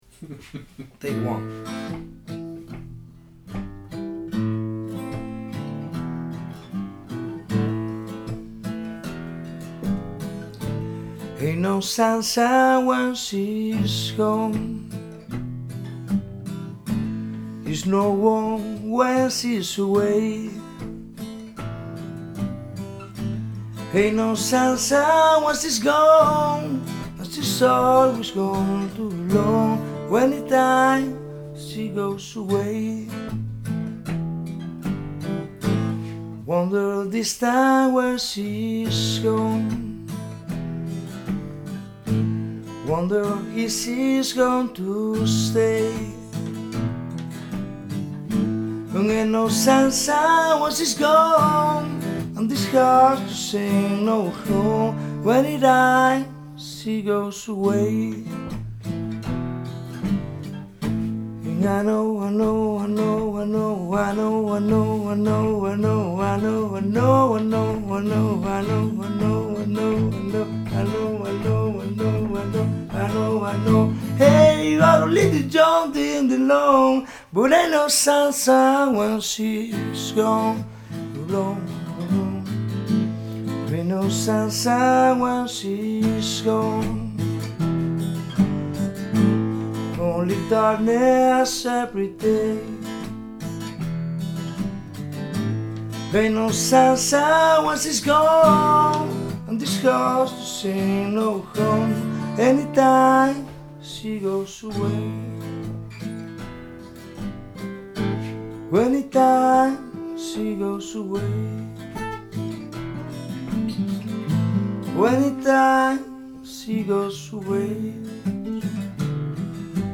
una versión rápida